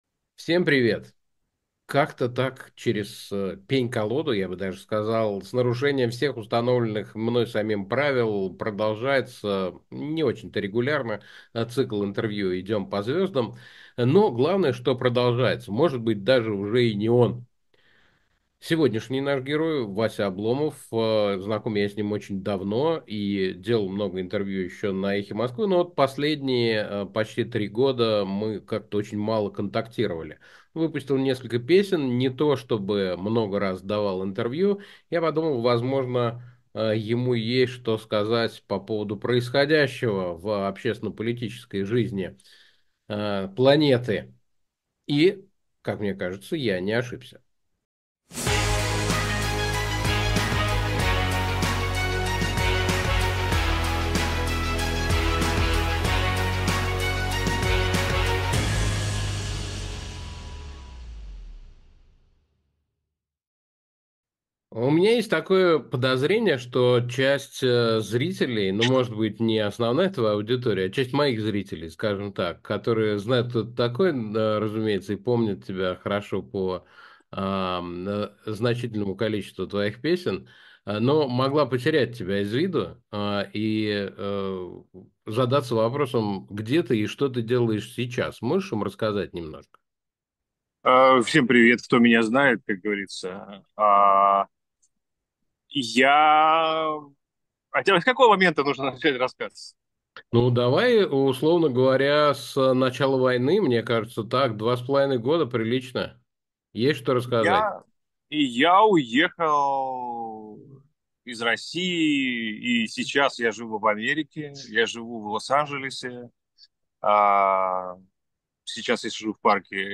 Как-то так, через пень-колоду, я бы даже сказал, с нарушением всех установленных мной самим правил продолжается не очень-то регулярно, но цикл интервью «Идем по звездам».